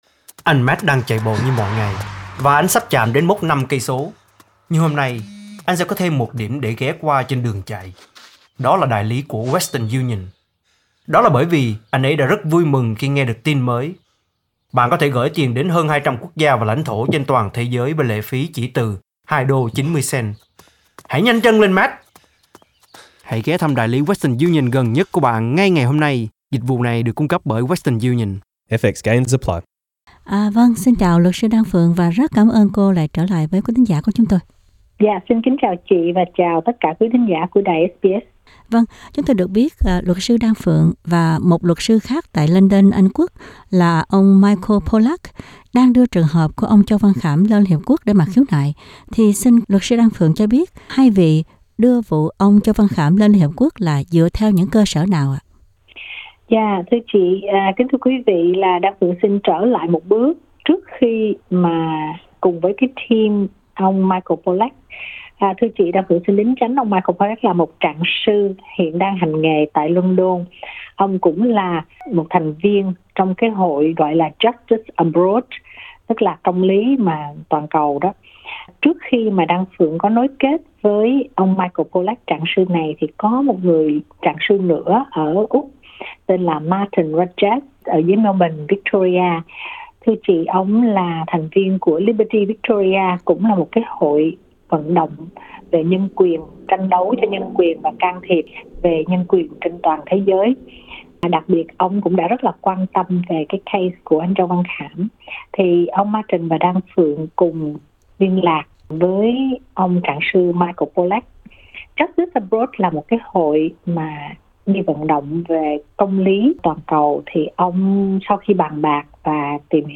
Trong phần phỏng vấn đầu trang